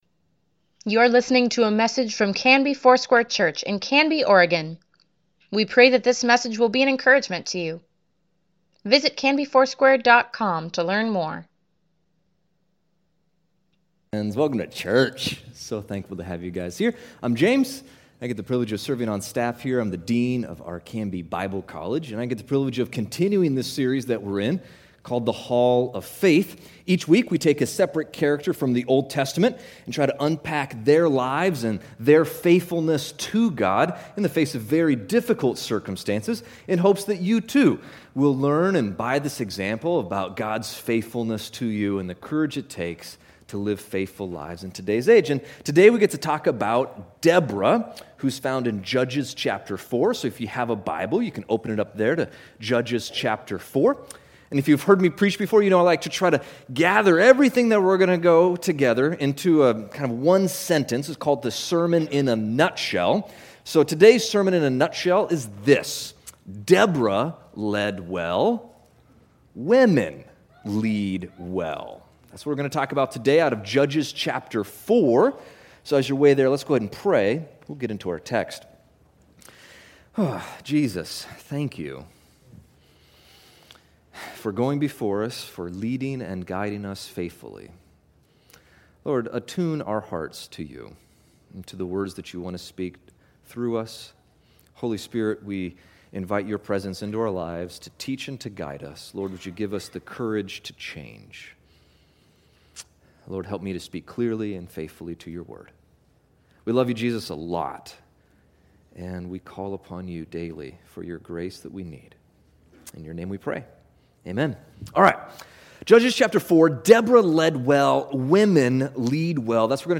Weekly Email Water Baptism Prayer Events Sermons Give Care for Carus Deborah February 17, 2019